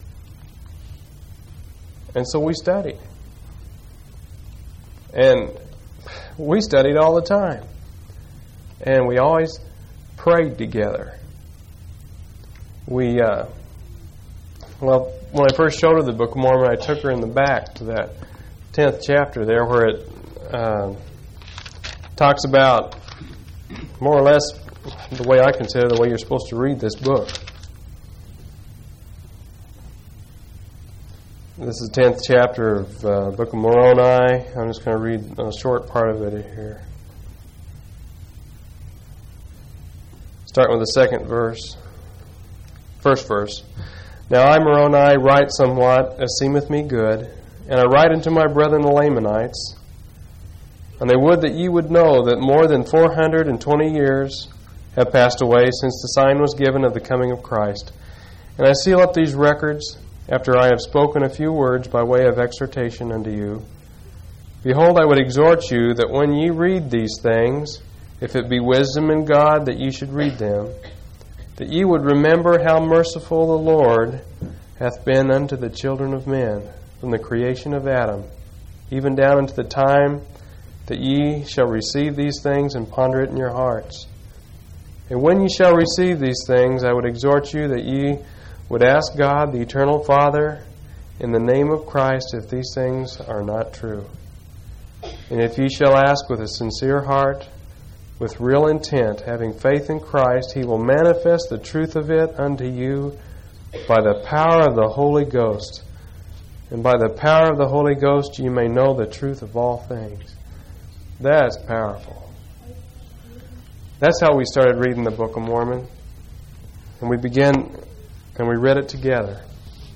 10/16/1988 Location: Collins Rally Event